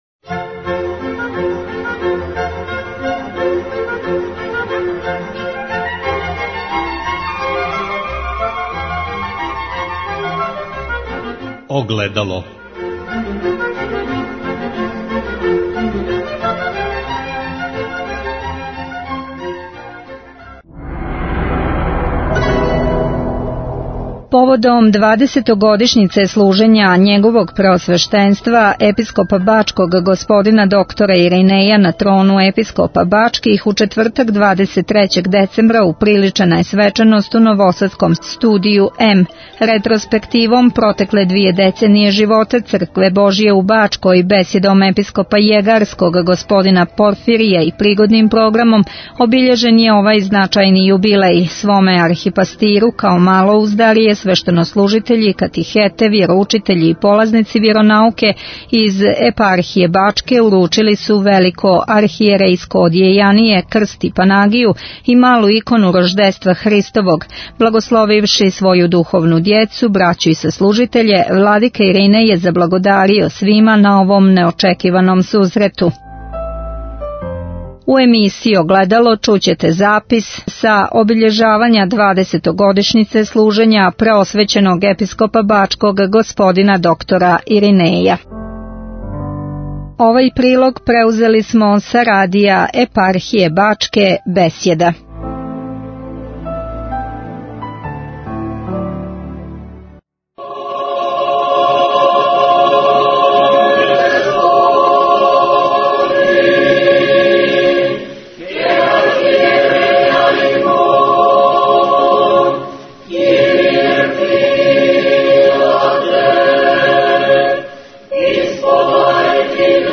Запис са свечаности обиљежавања 20-огодишњице служења Његовог Преосвештенства Епископа др Иринеја на трону Епископа Бачких, која је одржана у четвртак 23. децембра у новосадском Студију М. Прилог смо преузели са Радија Епархије Бачке "Беседа".
Ретроспективом протекле двије деценије живота Цркве Божје у Бачкој, бесједом Епископа јегарског Господина Порфирија и пригодним програмом обиљежен је овај значајни јубилеј.